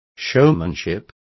Also find out how teatralidad is pronounced correctly.